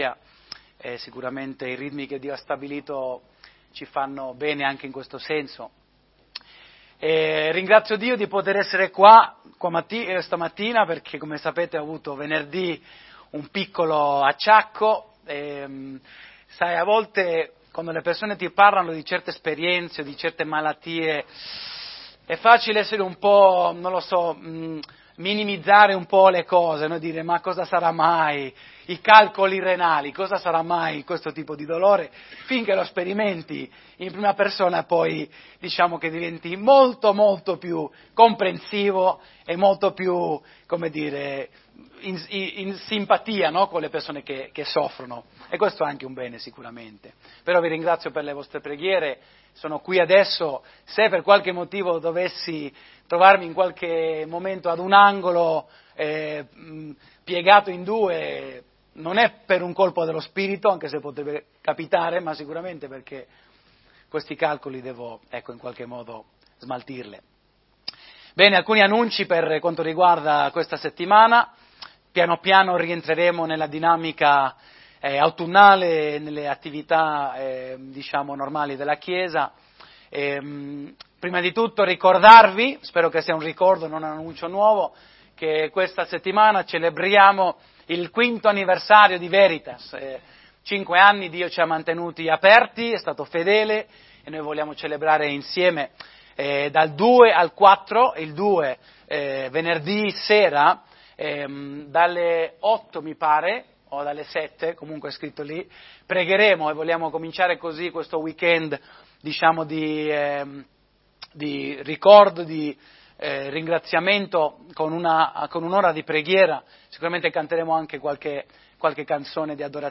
Tutti i sermoni Fedeltà 28 Agosto